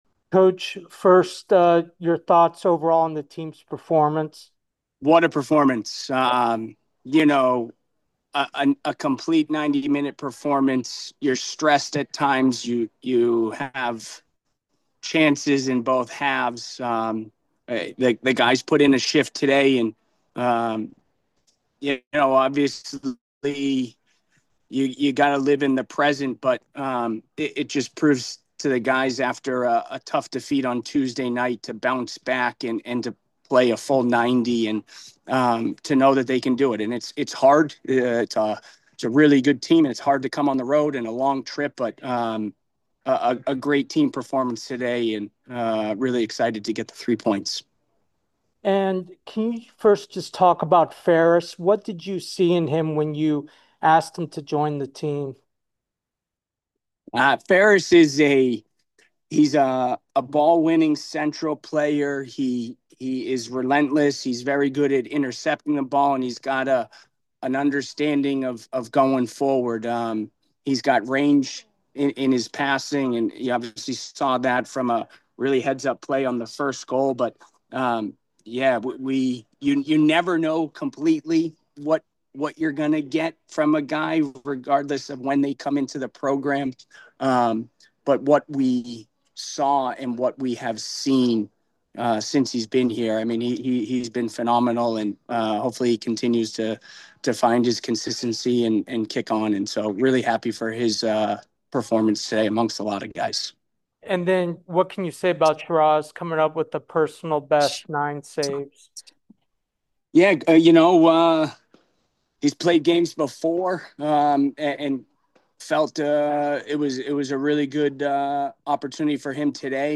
Bucknell Postgame Interview